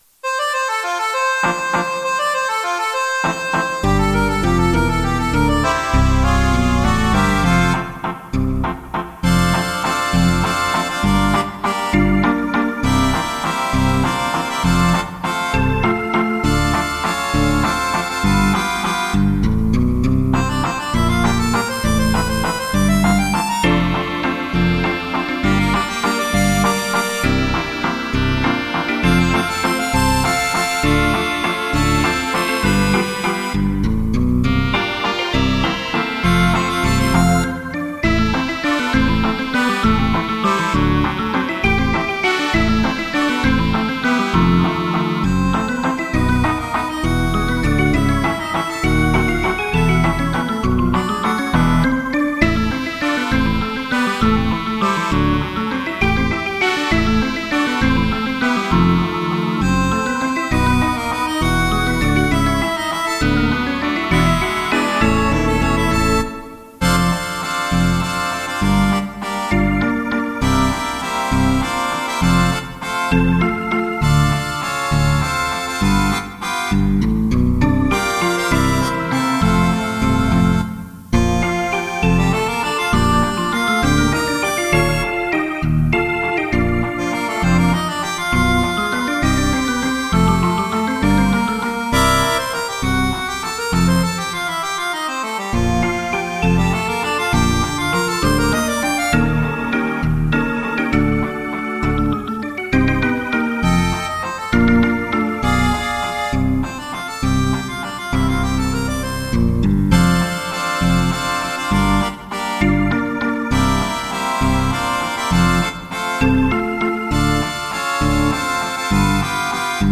для аккордеона и баяна
Вальс